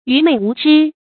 愚昧无知 yú mèi wú zhī
愚昧无知发音
成语正音愚，不能读作“yū”。